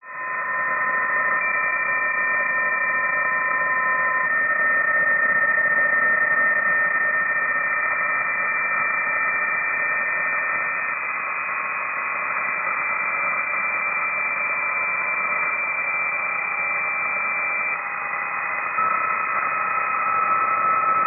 Here are the WAV files he generated with Doppler changing at